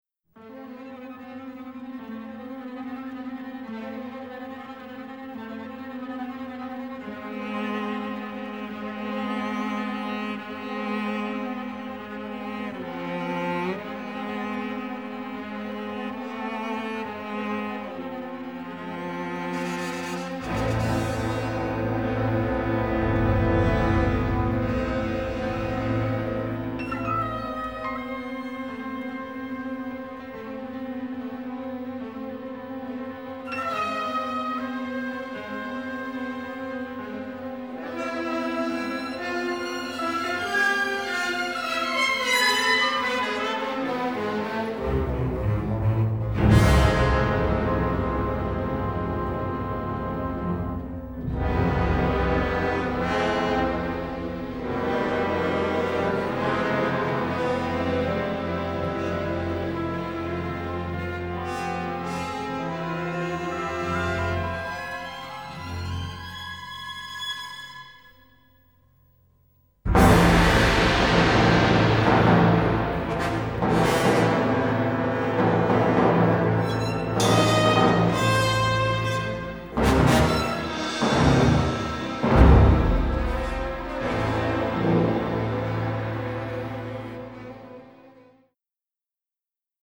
Trumpets, French horns keep everything at peak excitement.
Recorded in Germany.